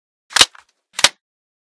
unjam.ogg